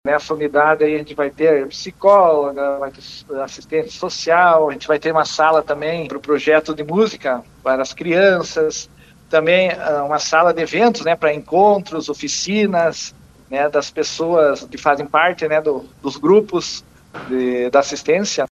Durante entrevista no programa de Bozano, pela RPI, ontem à noite, o secretário municipal de Saúde, Desenvolvimento Social, Habitação e Meio Ambiente, Cléber Denes, disse que a edificação, de aproximadamente 400 metros quadrados, vai ser feita em terreno que era do governo estadual, repassado para a prefeitura, nas imediações da escola estadual de Ensino Médio Doutor Bozano.